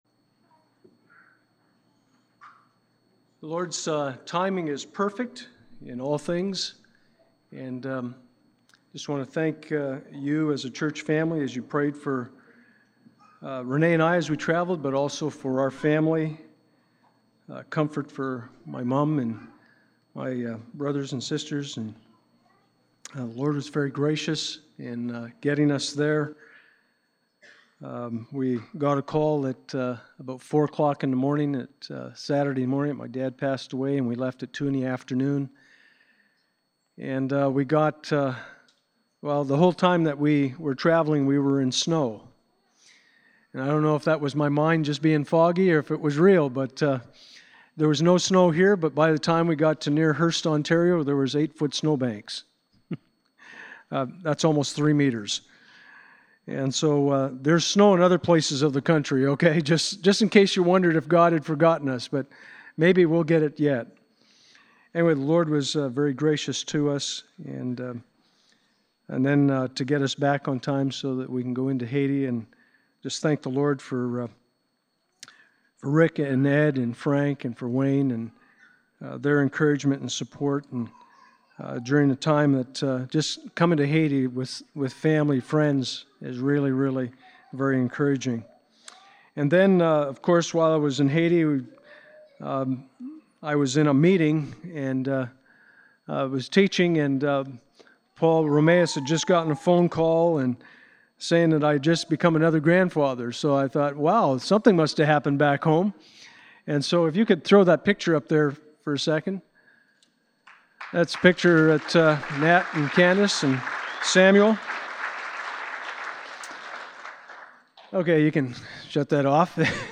Passage: Psalm 104:1-9 Service Type: Sunday Morning « We All Want Justice…